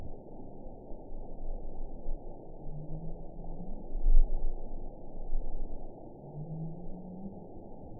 event 910139 date 01/16/22 time 02:14:17 GMT (3 years, 10 months ago) score 5.58 location TSS-AB07 detected by nrw target species NRW annotations +NRW Spectrogram: Frequency (kHz) vs. Time (s) audio not available .wav